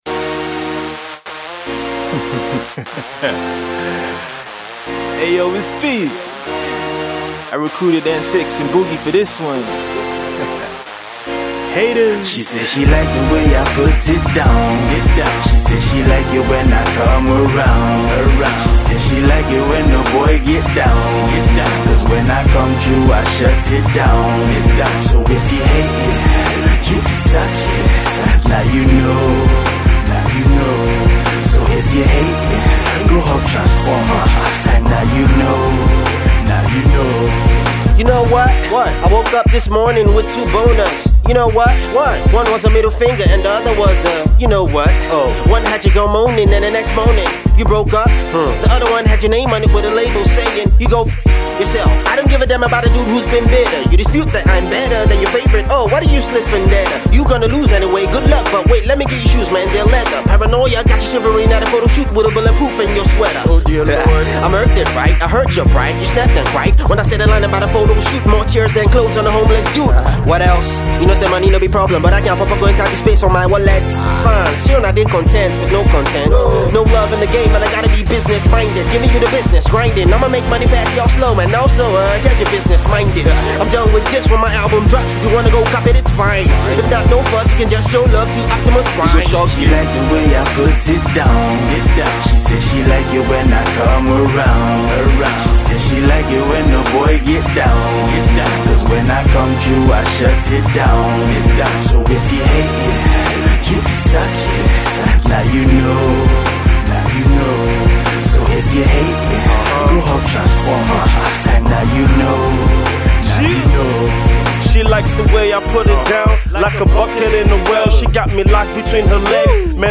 fast-spitting witty lyricist